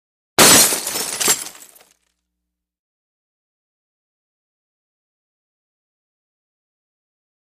Medium Shatter; Safety Glass; Medium Sized Crash / Sheet Of Glass Being Shattered; Close Perspective.